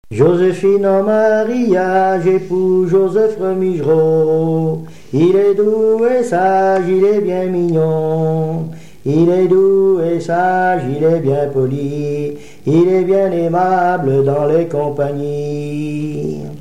chanson dite de "charivari"
circonstance : fiançaille, noce ;
Pièce musicale inédite